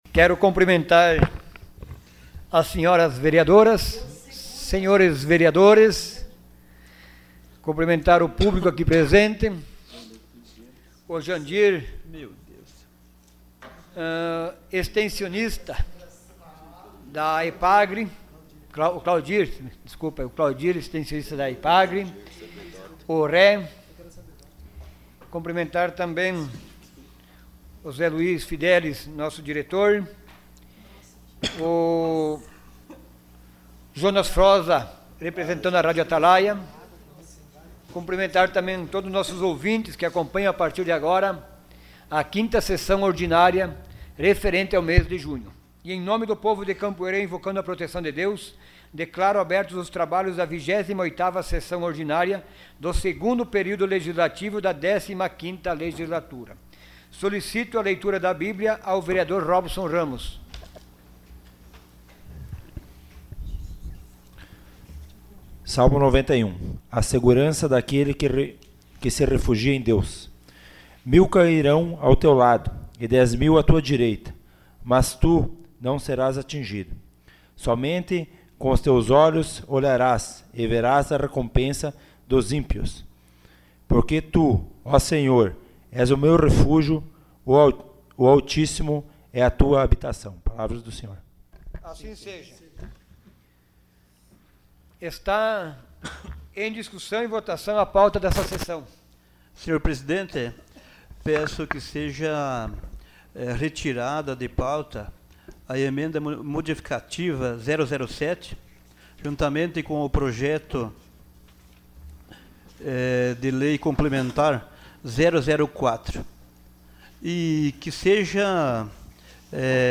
Sessão Ordinária dia 21 de junho de 2018.